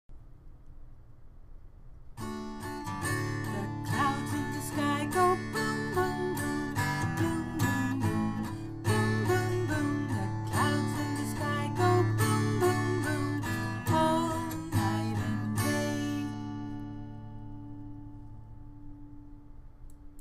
Tune: "The Wheels on the Bus"